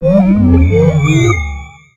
Cri de Mushana dans Pokémon X et Y.